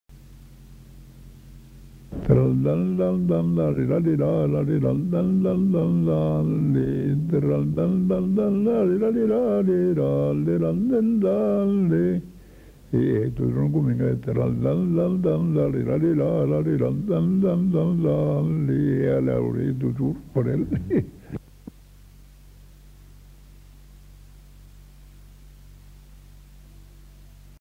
Aire culturelle : Haut-Agenais
Genre : chant
Effectif : 1
Type de voix : voix d'homme
Production du son : fredonné